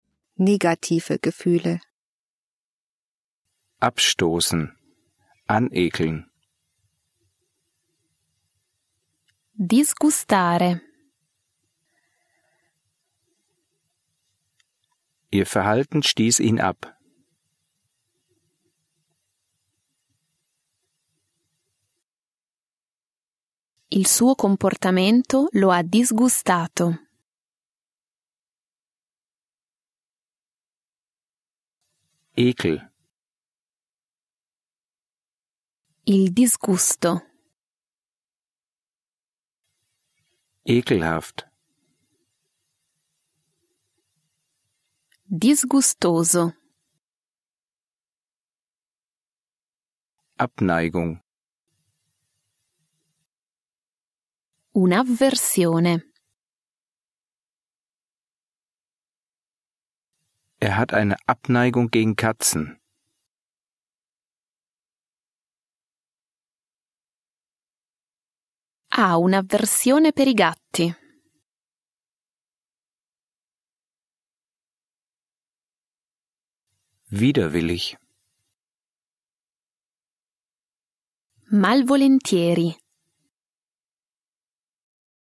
Übersetzungs- und Nachsprechpausen sorgen für die Selbstkontrolle.